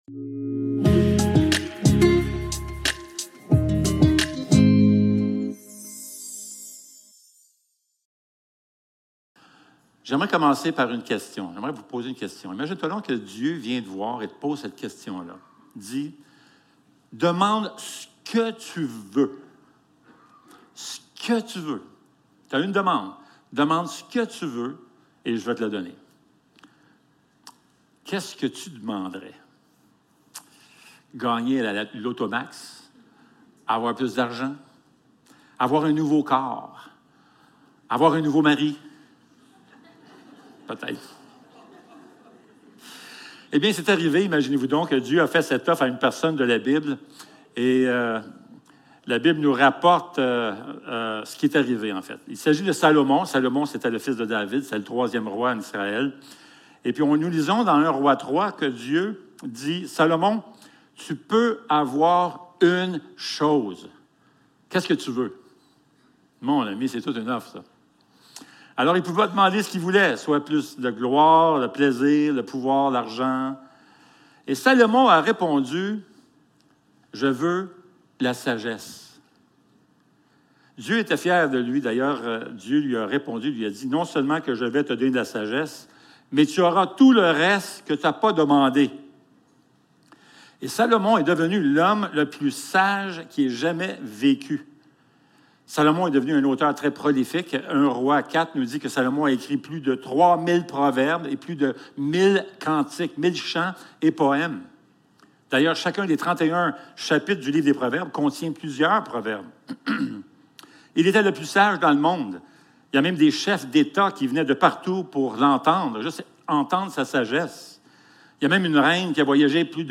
Proverbes 1.1-7 Service Type: Célébration dimanche matin L'Évangile dans les Proverbes #1 Quand on pense au livre des Proverbes